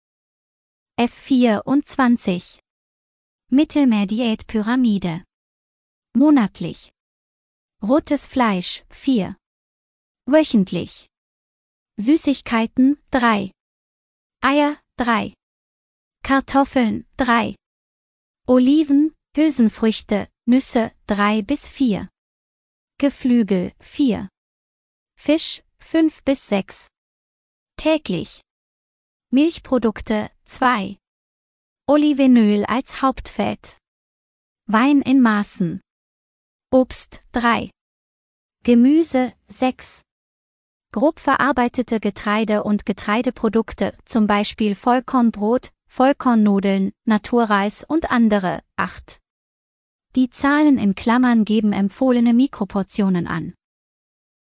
Geführte Audio-Tour